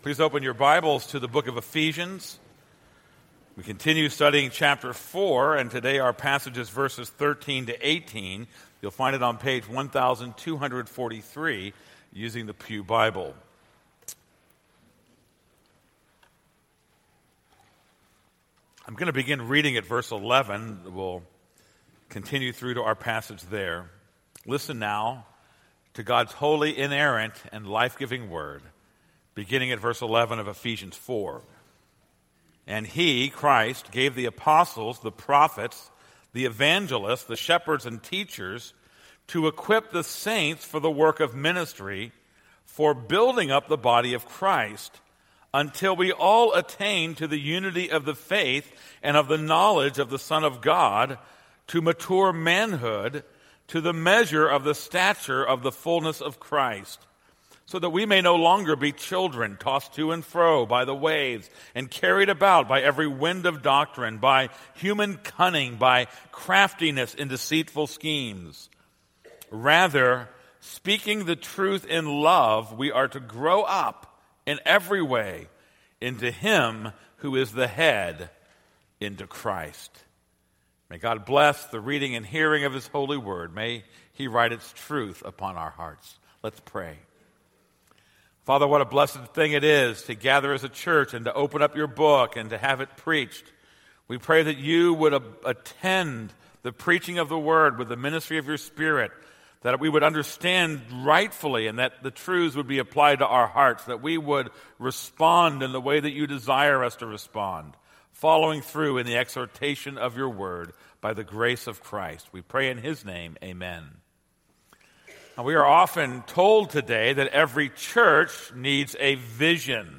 This is a sermon on Ephesians 4:13-15.